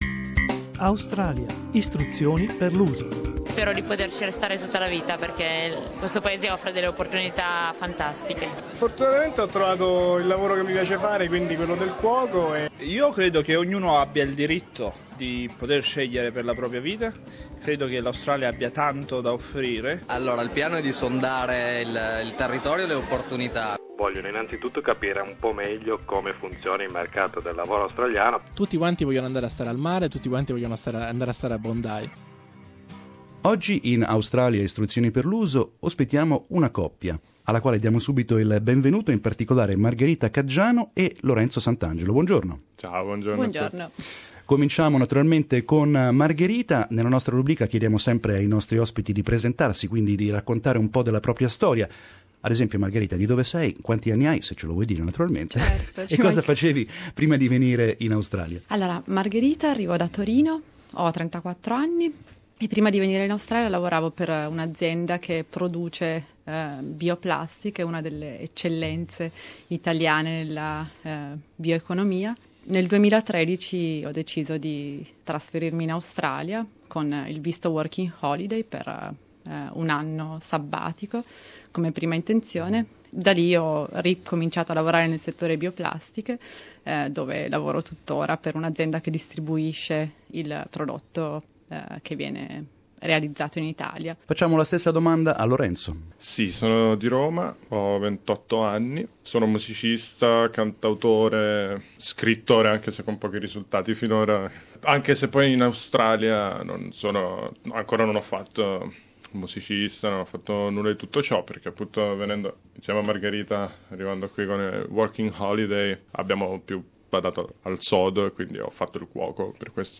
But, as you will hear in the interview, they went through difficult times in their early months in Sydney.